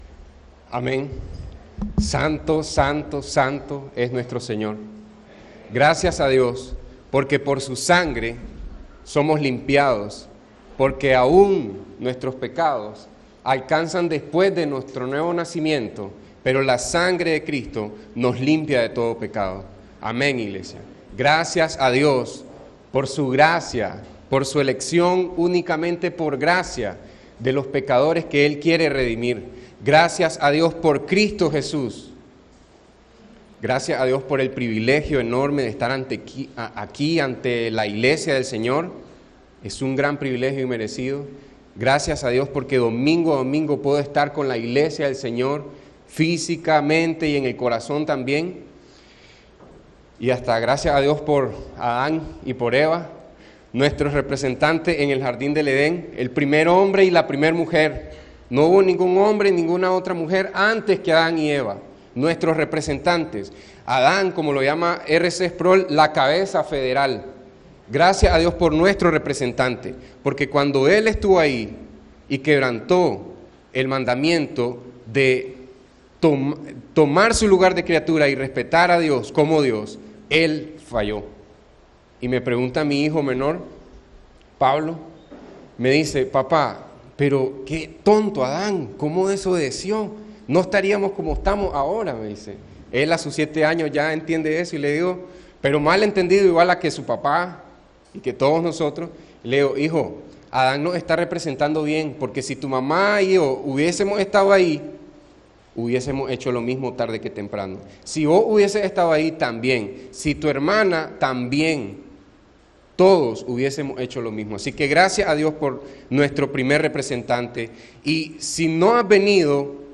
Escucha la prédica del domingo